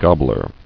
[gob·bler]